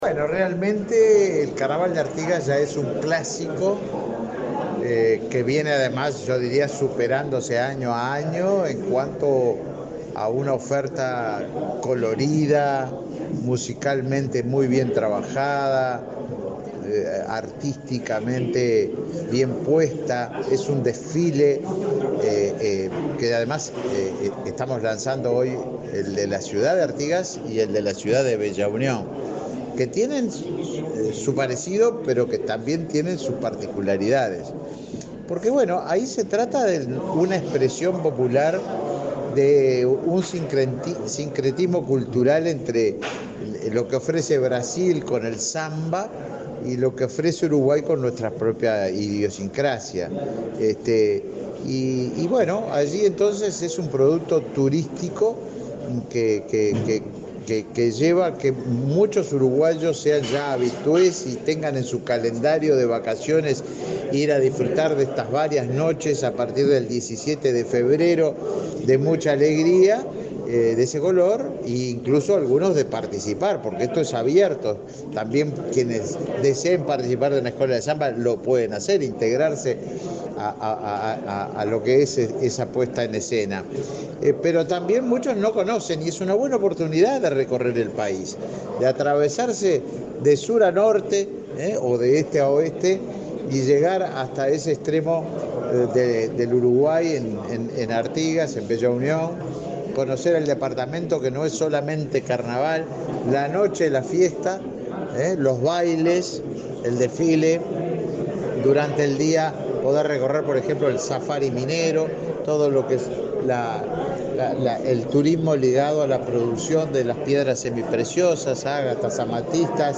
Declaraciones del ministro de Turismo, Tabaré Viera
El ministro de Turismo, Tabaré Viera, participó, en la sede de esa cartera, al lanzamiento del Carnaval de Artigas. Luego dialogó con la prensa.